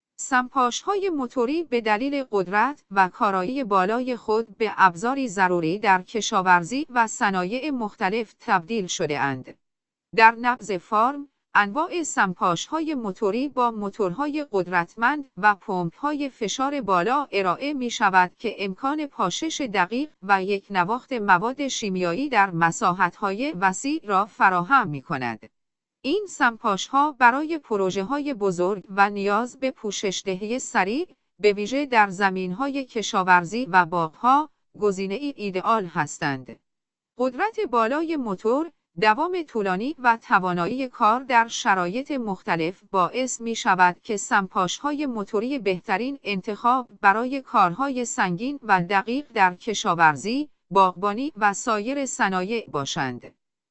Motor-sprayer.ogg